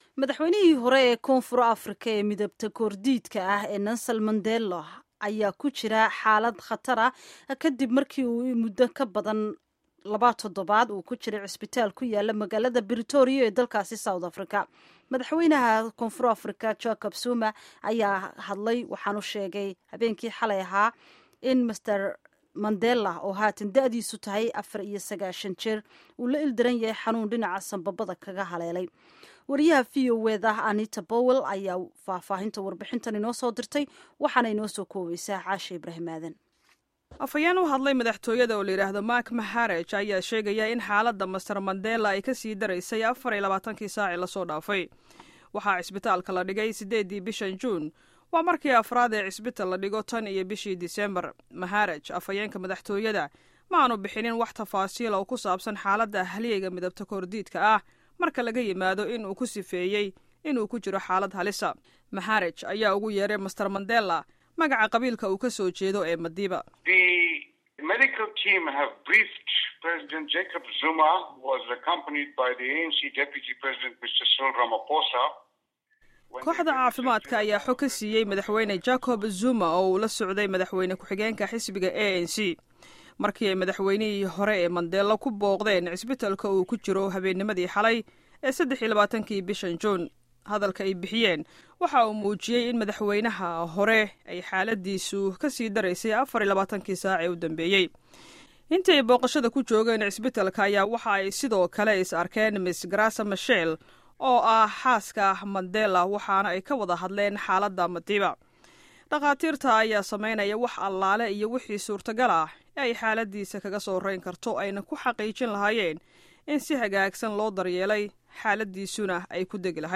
Warbixinta Xaaladda Caafimaadka ee Mandela